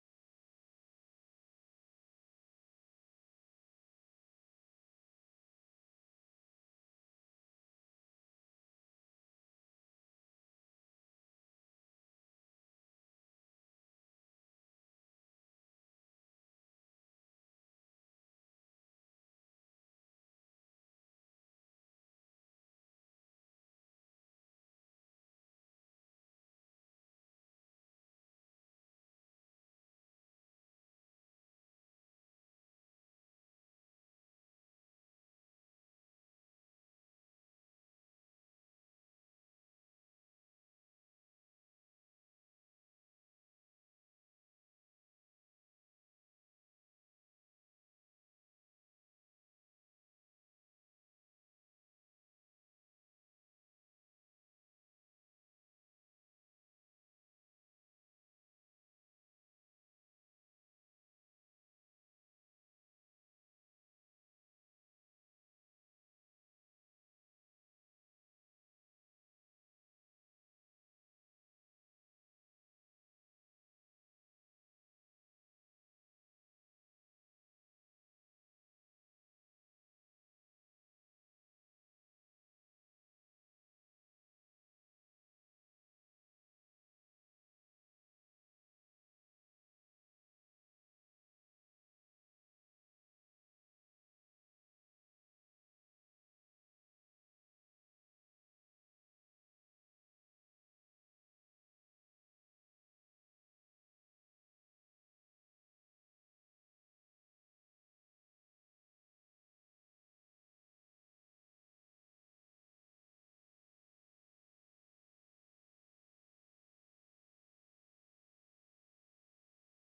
"The Irish Show" features the best of Irish music, sports results, requests, and interviews.